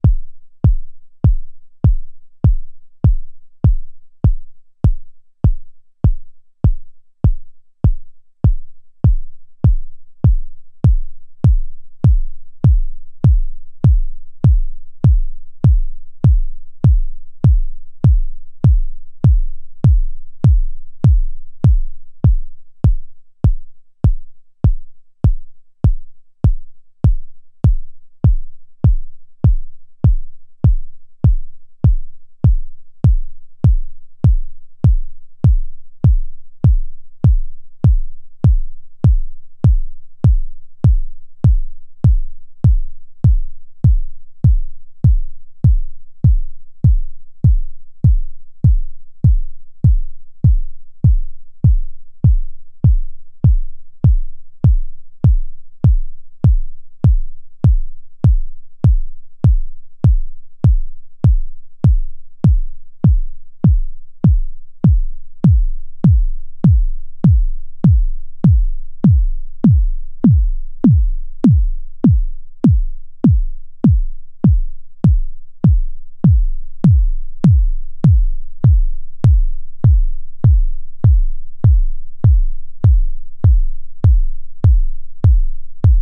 Nord Modular G1 Sine LFO used as an oscillator, modulated by a simple AD envelope (amp and pitch). Playing with the LFO phase looking for diff amount of attack click. No filters or FX used. Just G1 raw sound.